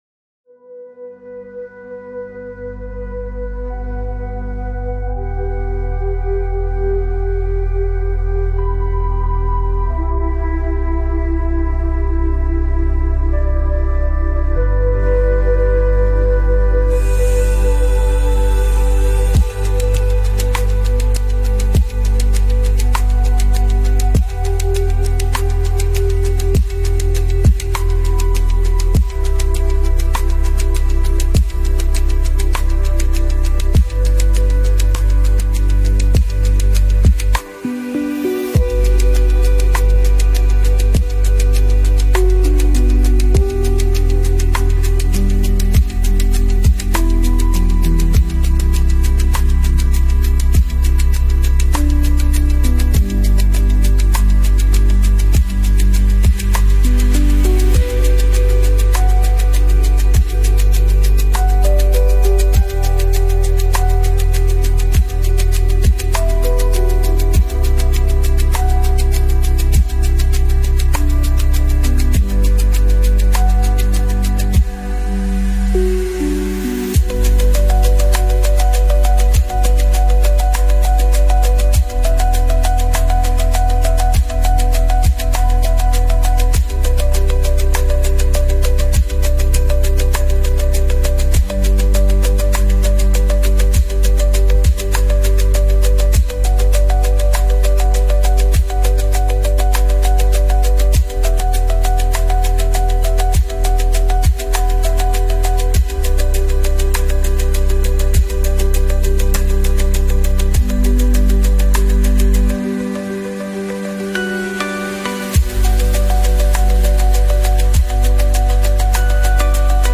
Deep-Space Relaxation & Focus & Meditation Music
seamless 7 min 54 sec ambient soundscape
Mastered in Mixea (Intensity-Low / EQ-Brighter)
ethereal space ambience